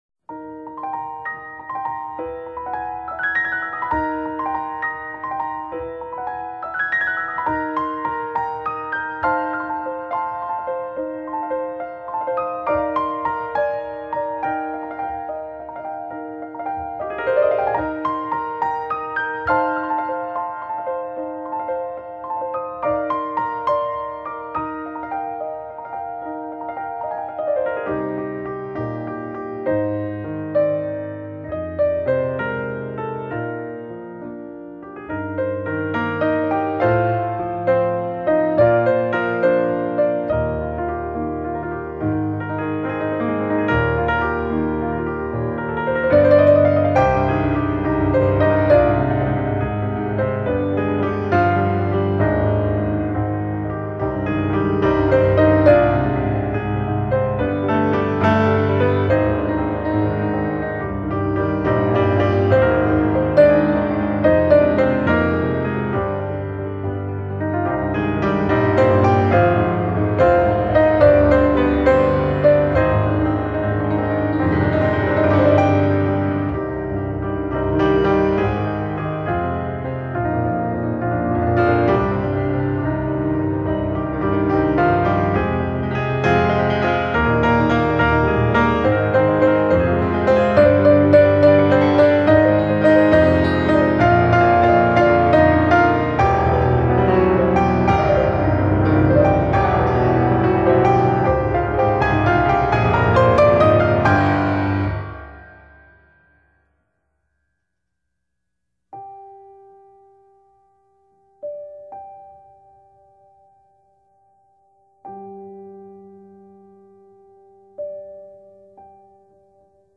Hymn arrangement